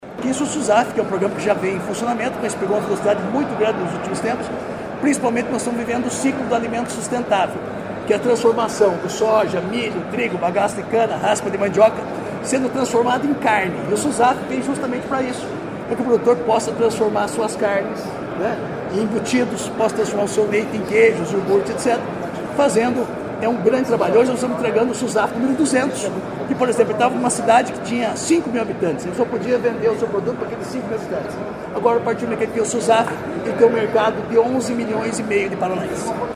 Sonora do secretário Estadual da Agricultura e Abastecimento, Marcio Nunes, sobre a entrega do Selo Susaf a Campo Mourão, 200ª cidade a receber a certificação